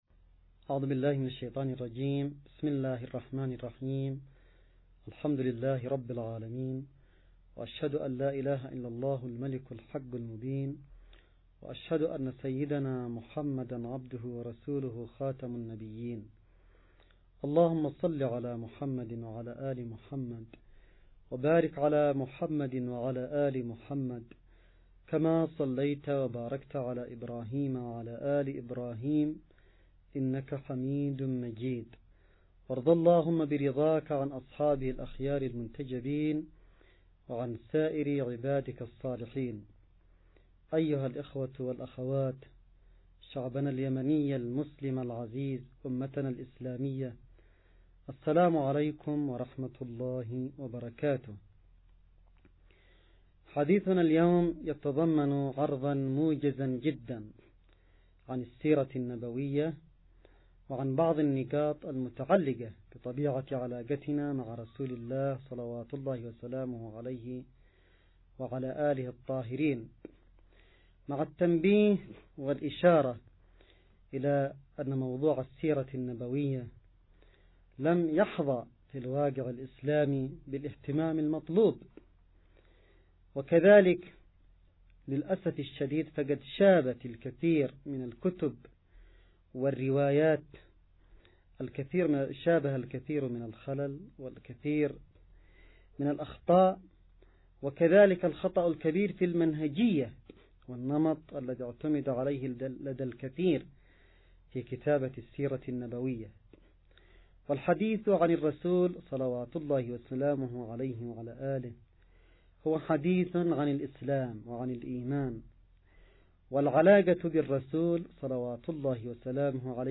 نص + فيديو + أستماع لمحاضرة السيد عبدالملك بدرالدين الحوثي – المولد النبوي 1439هـ 11 ربيع اول – المحاضرة الخامسة
المحاضرة_الخامسة_للسيد_عبدالملك.mp3